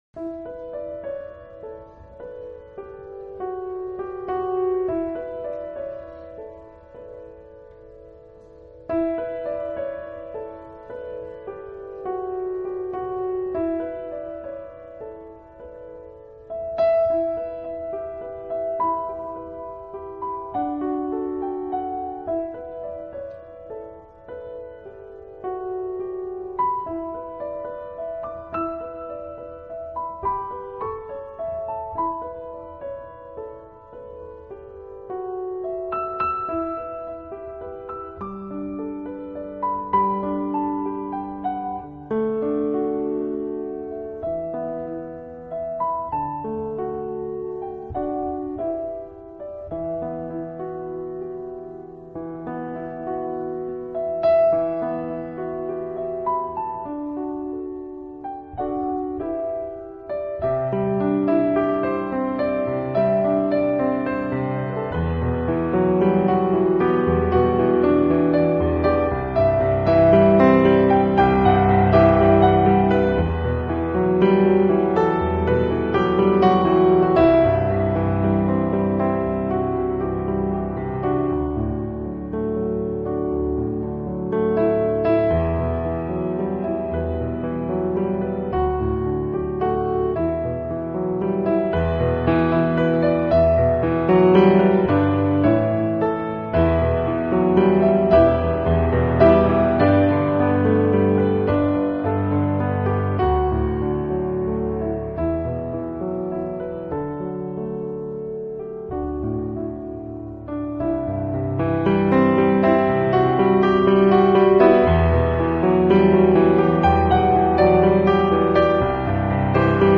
音乐风格：New Age/piano